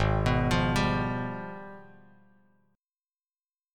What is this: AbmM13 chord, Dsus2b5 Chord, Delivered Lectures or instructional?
AbmM13 chord